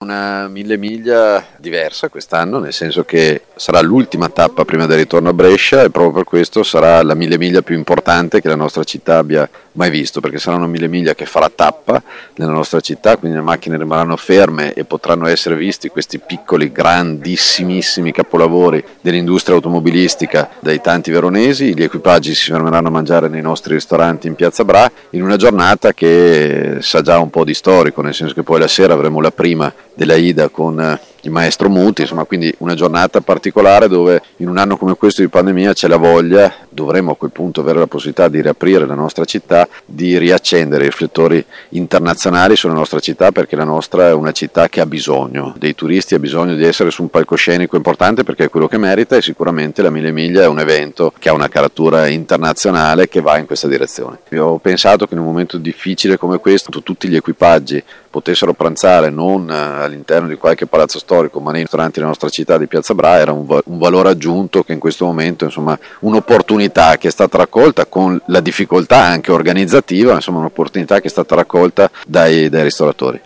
Sindaco-di-Verona-Federcio-Sboarina-sulla-Mille-Miglia-2021.mp3